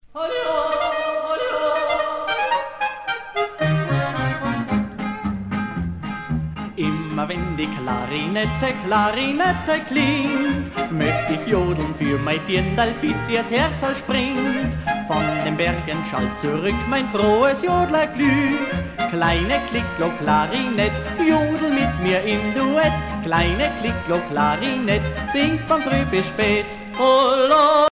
danse : fox-trot
Pièce musicale éditée